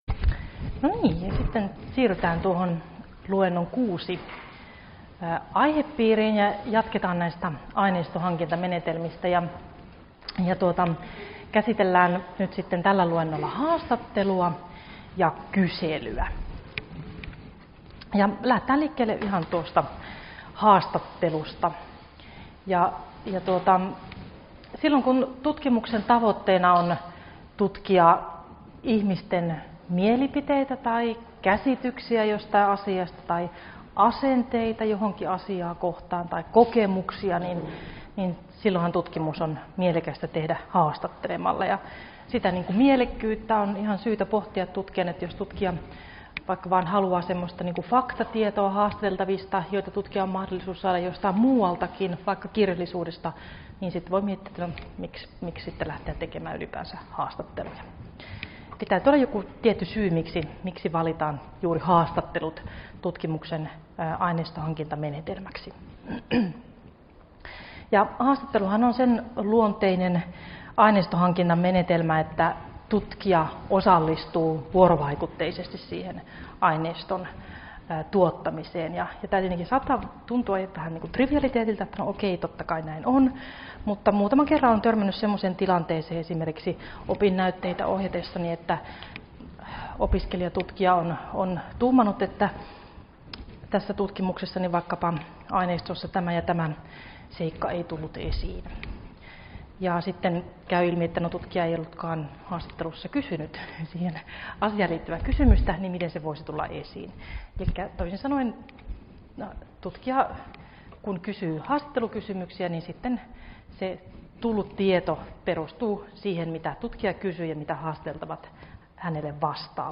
Luento 6 - Aineistonhankintamenetelmiä 2 — Moniviestin